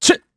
Clause_ice-Vox_Jump_kr.wav